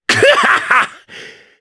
Ricardo-Vox_Happy2_jp.wav